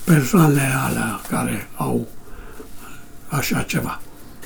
Original creative-commons licensed sounds for DJ's and music producers, recorded with high quality studio microphones.
old man voice speaking 60 meters.wav
old_man_voice_speaking_about_some_people_lr4_tFl.wav